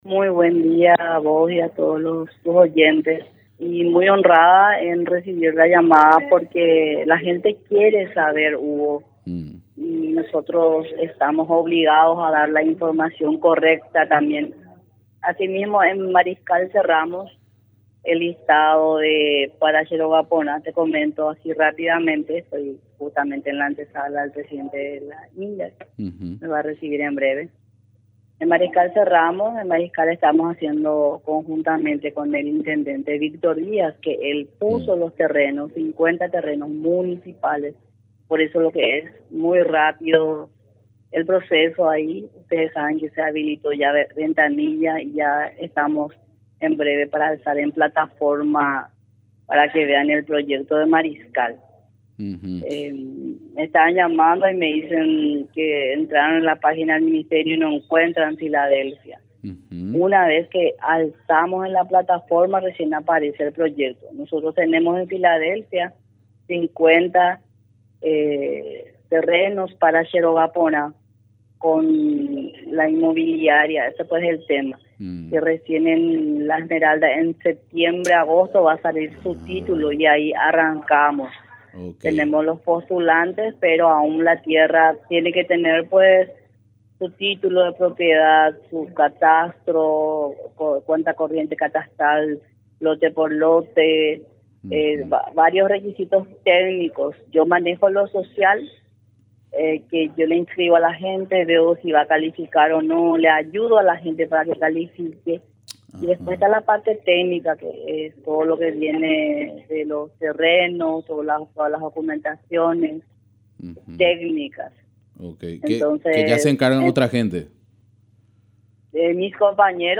Entrevistas / Matinal 610 Programa Che Róga Pora en el chaco Jul 25 2024 | 00:11:28 1x 00:00 / 00:11:28 Subscribe Share RSS Feed Share Link Embed